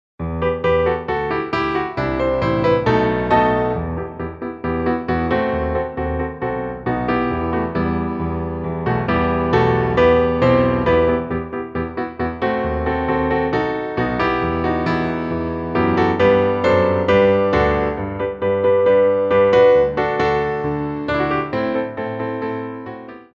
Piano Arrangements
Degagés
4/4 (16x8)